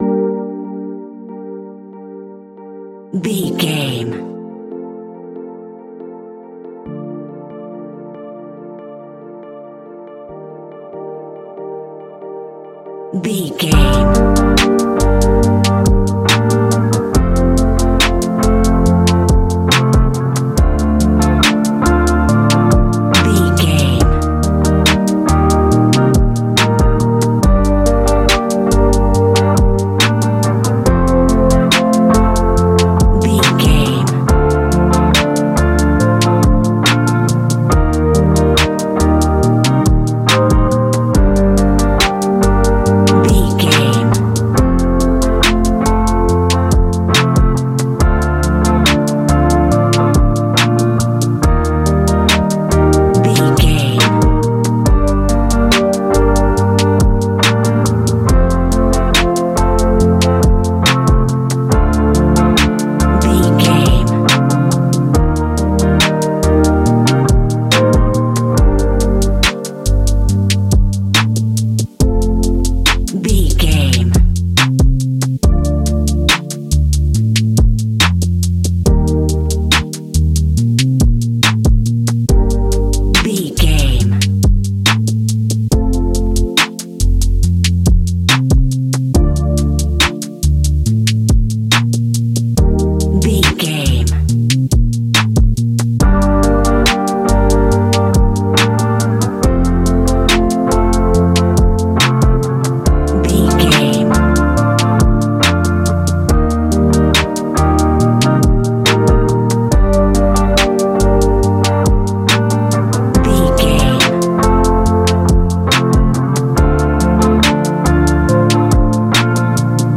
Ionian/Major
A♯
chill out
laid back
Lounge
sparse
new age
chilled electronica
ambient
atmospheric
instrumentals